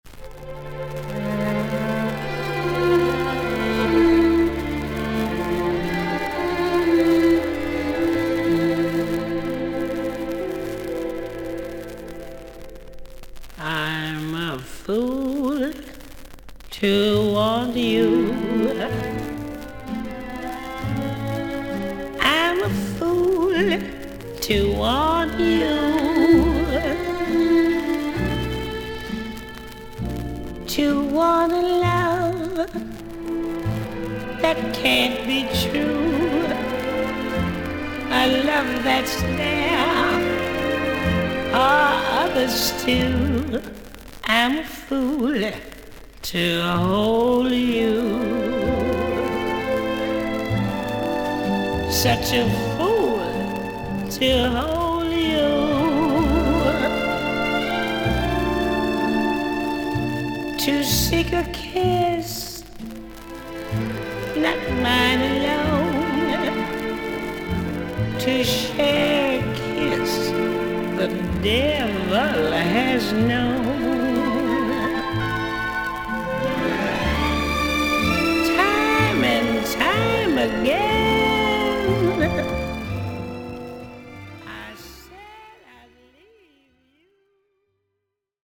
少々軽いパチノイズの箇所あり。少々サーフィス・ノイズあり。クリアな音です。
アメリカを代表する女性ジャズ・シンガー。